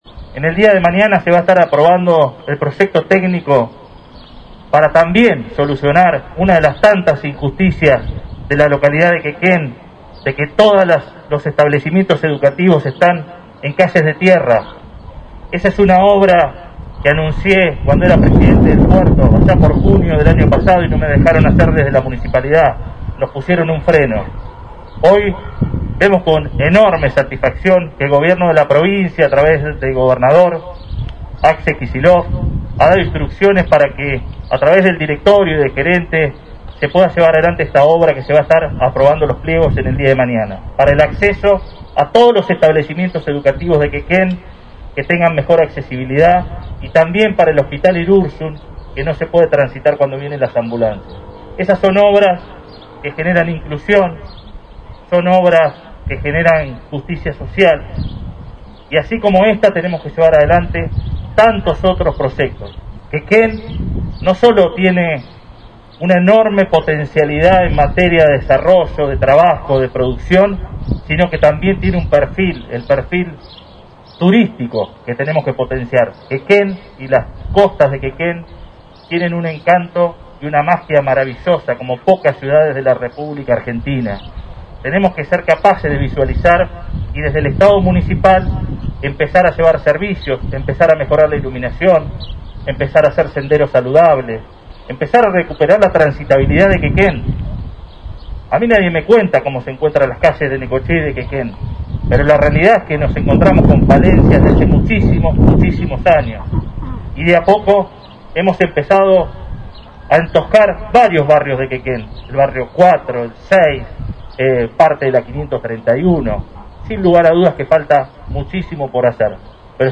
En la mañana de este lunes 3 de agosto, durante el acto en el que se conmemoraron los primeros 166 años de vida de Quequén, el intendente Arturo Rojas anunció la inminente aprobación por parte de la Provincia de Buenos Aires de un proyecto técnico que había presentado cuando aún desempeñaba funciones como Presidente del Consorcio de Gestión de Puerto Quequén y que, desde la anterior gestión de gobierno, le habían denegado.